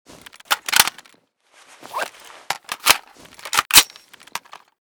g36_reload_empty.ogg.bak